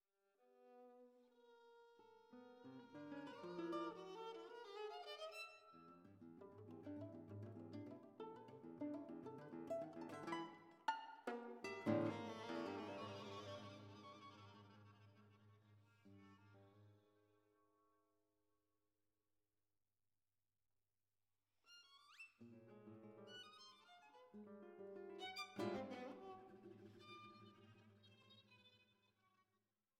serieller Musik